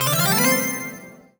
collect_item_jingle_03.wav